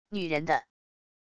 女人的wav音频
女人的wav音频生成系统WAV Audio Player